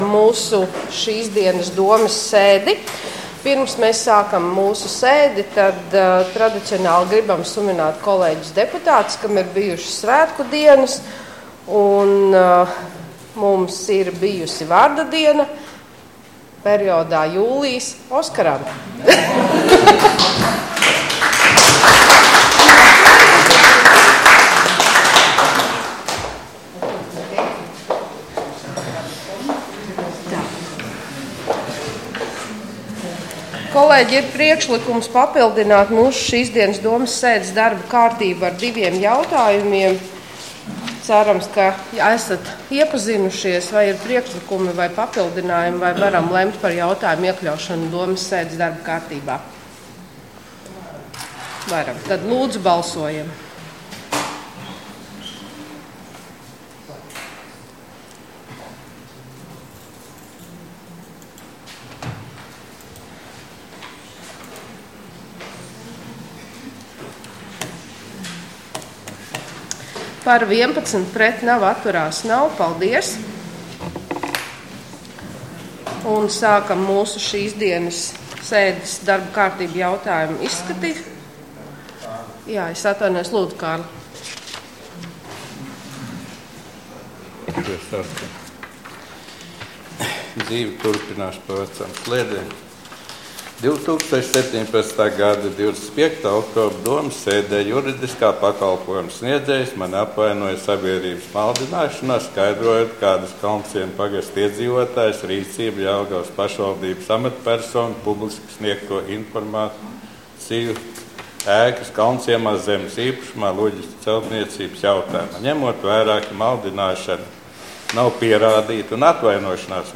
Domes sēde Nr. 13